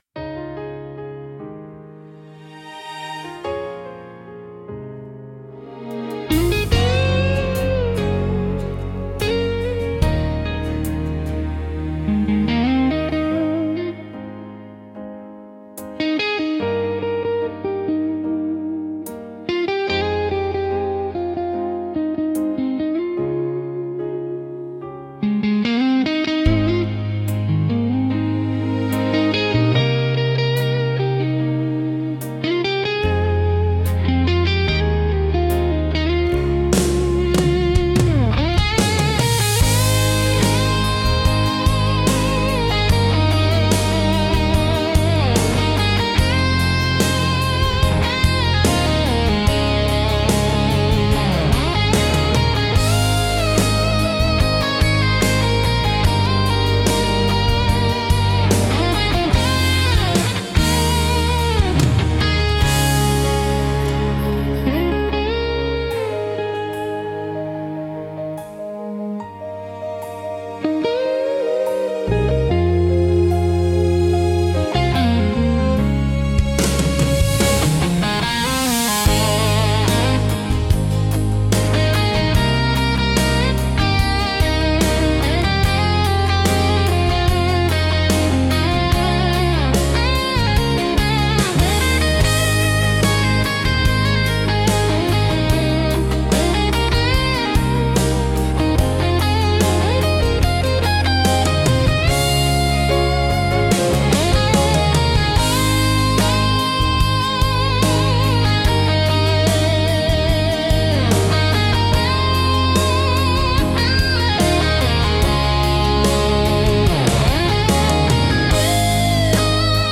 聴く人に新鮮な発見と共感をもたらし、広がりのある壮大な空間を演出するジャンルです。